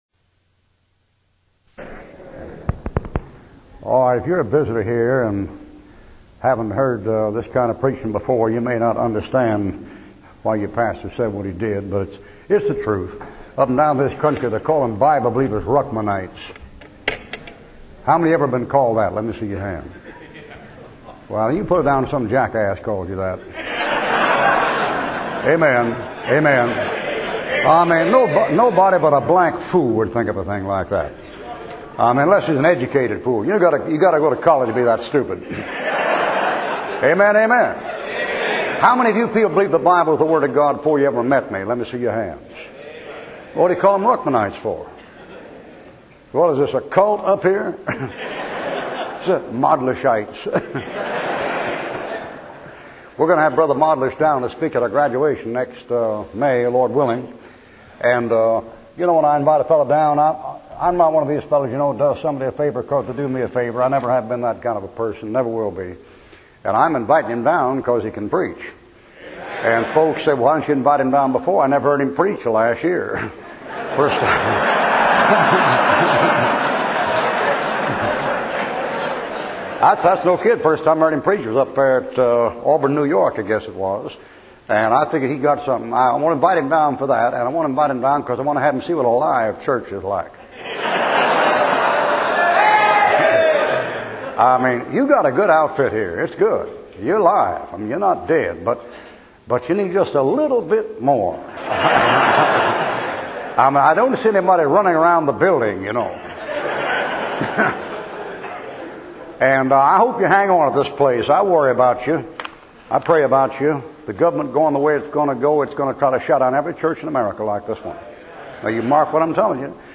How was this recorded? the-great-white-throne-preached-at-rochester-ny.mp3